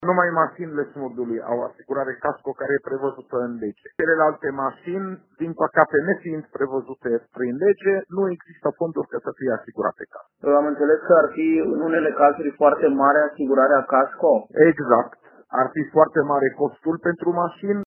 Secretarul de stat Raed Arafat a explicat de ce nu au mașinile de pompieri asigurare CASCO.